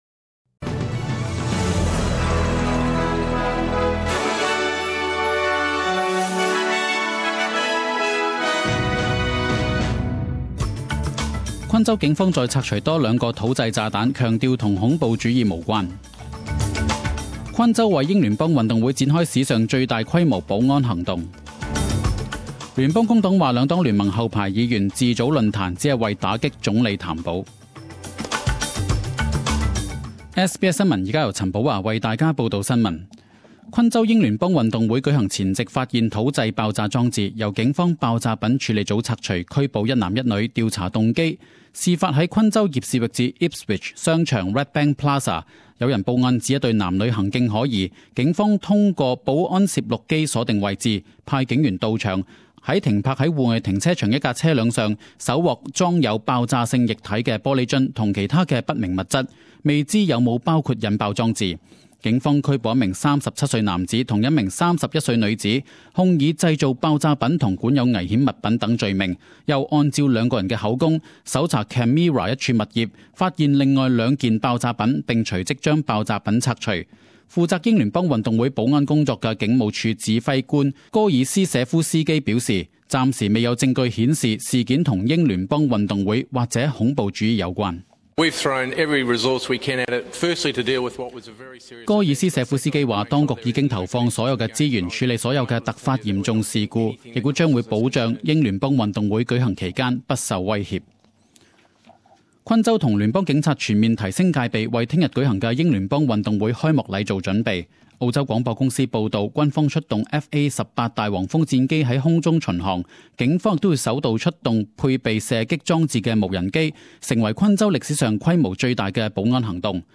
十点钟新闻报导 (4月3日)